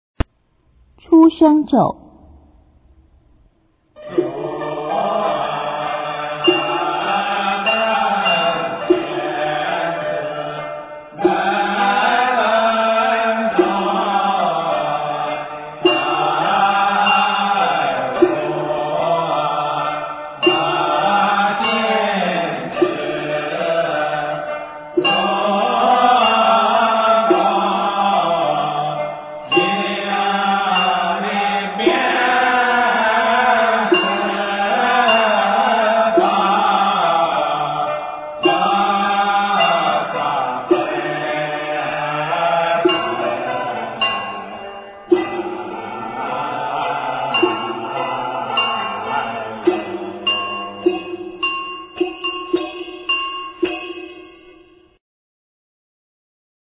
中国道教音乐 全真正韵 出生咒